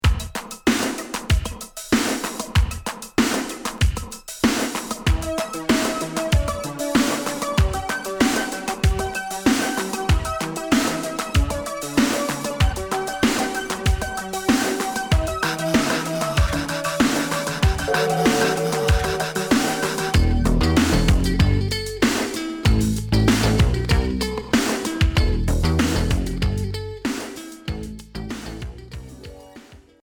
Minimal synth Deuxième 45t retour à l'accueil